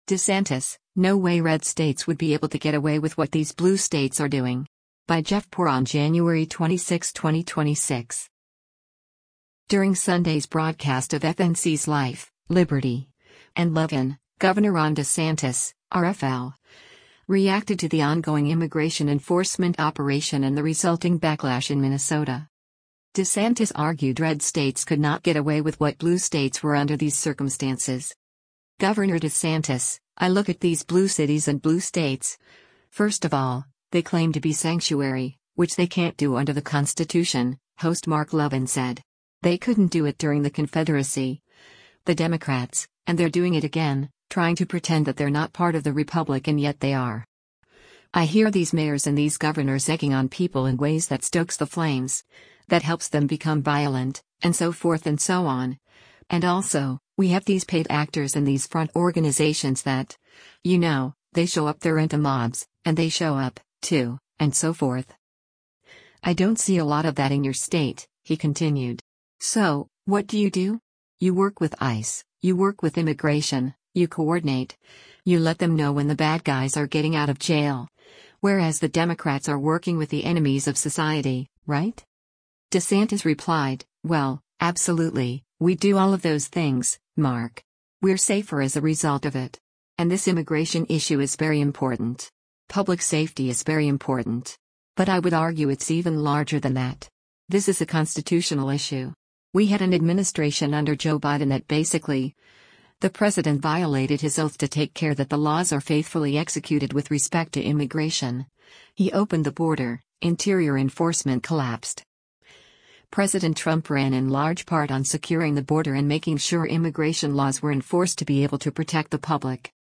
During Sunday’s broadcast of FNC’s “Life, Liberty & Levin,” Gov. Ron DeSantis (R-FL) reacted to the ongoing immigration enforcement operation and the resulting backlash in Minnesota.